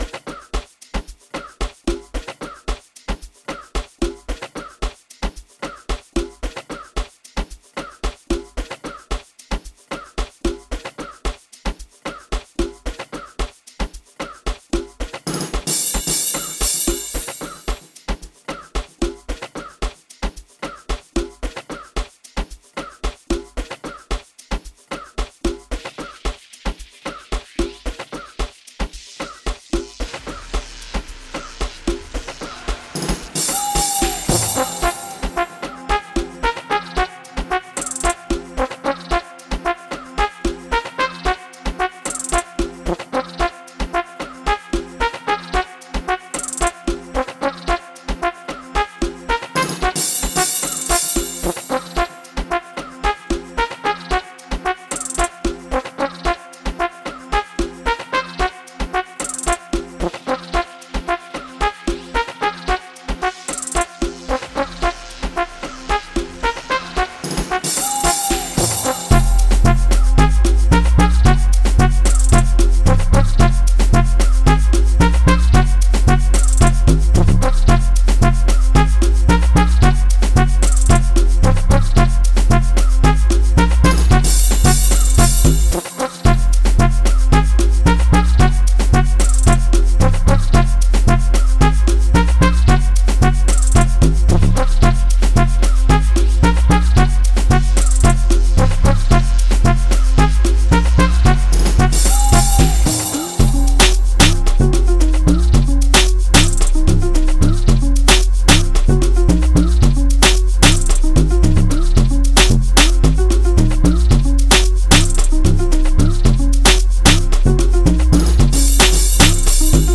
06:58 Genre : Amapiano Size